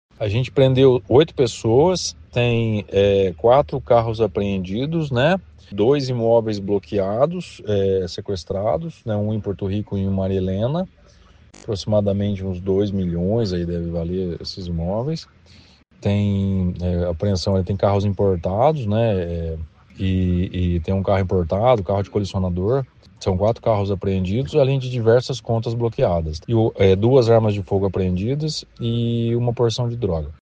A operação Medellín foi deflagrada nesta quinta-feira (24) pela Polícia Civil nas cidades de Maringá, Nova Londrina, Loanda e Porto Rico.